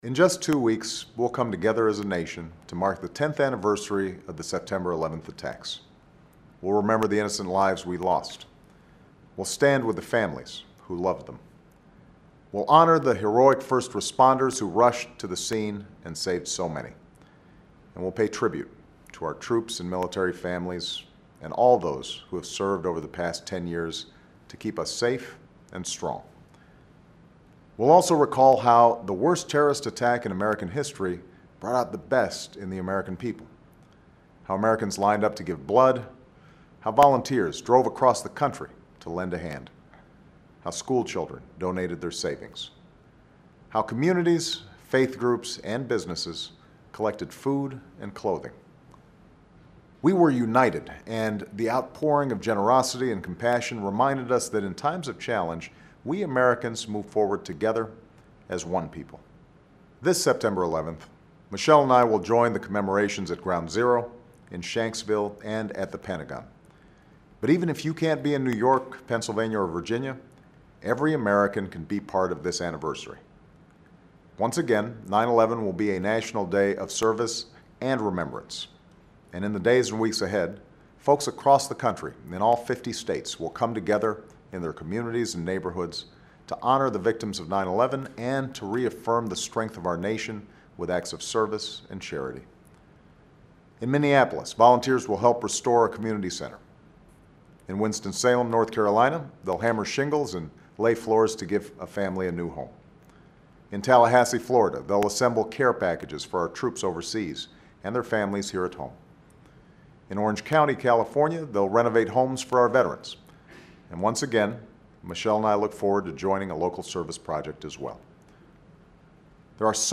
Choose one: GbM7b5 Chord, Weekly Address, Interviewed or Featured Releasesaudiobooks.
Weekly Address